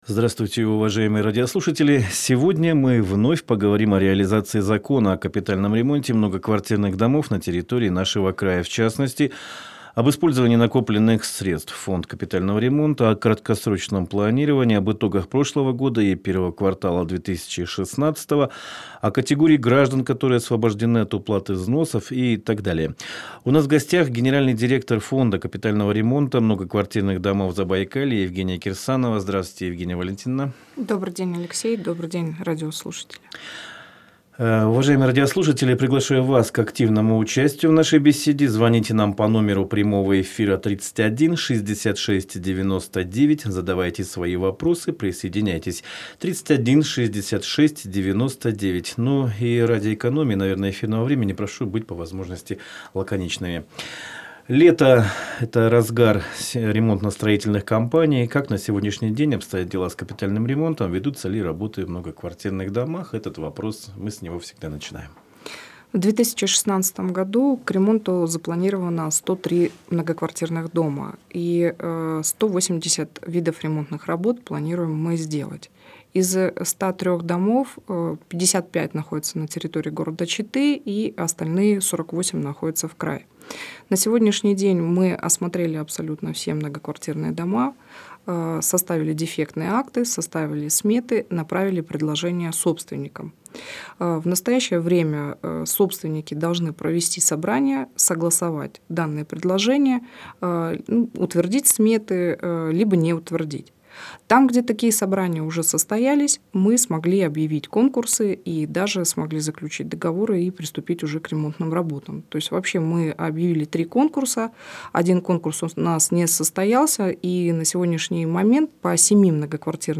ответила на вопросы радиослушателей
в прямом эфире